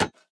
Minecraft Version Minecraft Version 1.21.5 Latest Release | Latest Snapshot 1.21.5 / assets / minecraft / sounds / block / heavy_core / step1.ogg Compare With Compare With Latest Release | Latest Snapshot
step1.ogg